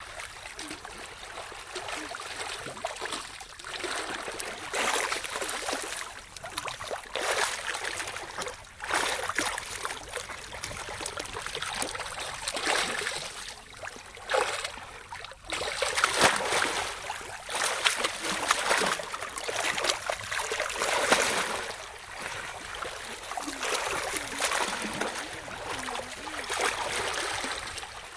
На этой странице собраны звуки тонущего человека в разных ситуациях: паника, борьба за жизнь, захлебывание водой.
Звук барахтанья человека в воде и утопления